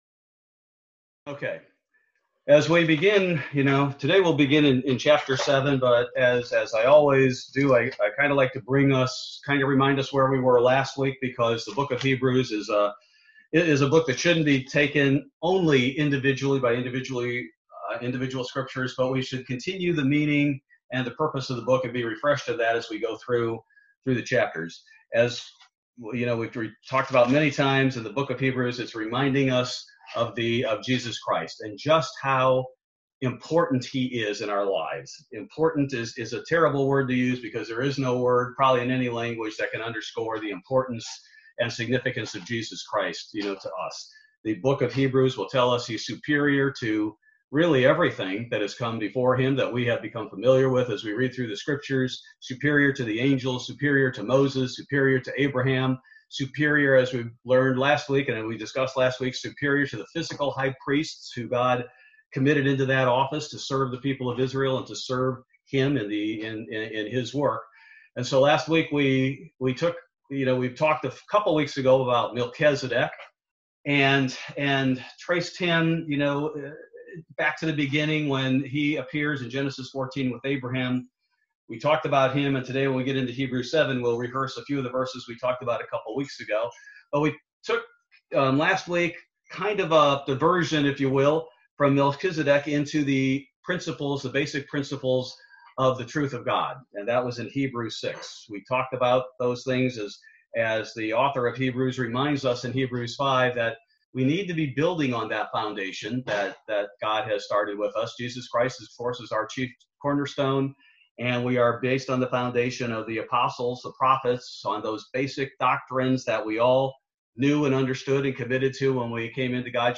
Bible Study: December 30, 2020